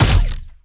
Plasmid Machinegun
GUNAuto_Plasmid Machinegun Single_05_SFRMS_SCIWPNS.wav